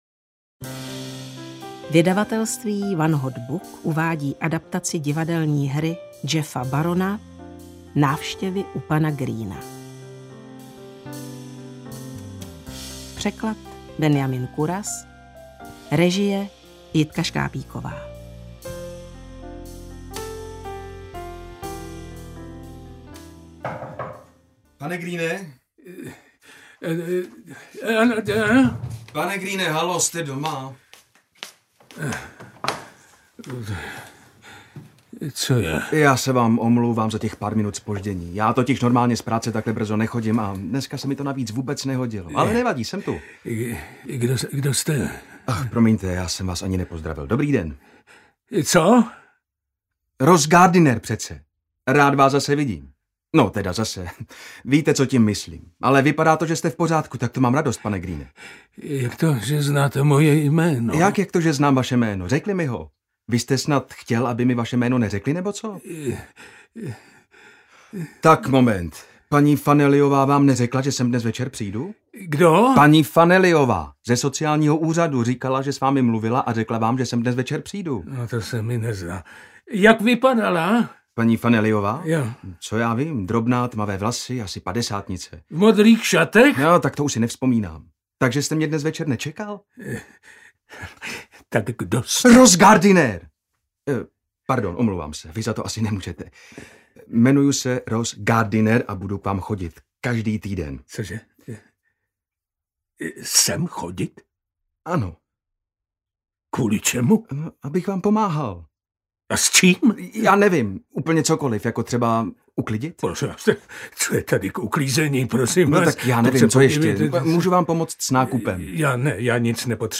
Návštěvy u pana Greena audiokniha
Ukázka z knihy
• InterpretViktor Preiss, Ivan Lupták